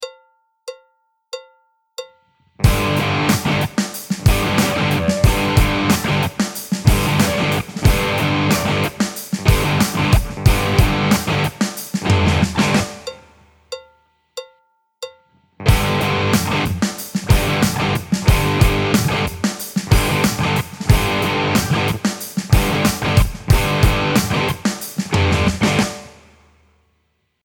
ミュートの実践フレーズ
まったく同じフレーズを弾き分けた動画の、特に音の「隙間」に注目して聴き比べてみてください。
やはり1つ目の弾き方だと、全体的にルーズな印象を受けます。
2つ目の弾き方だと、全体的にかっちりとした印象を受けます。
mute.mp3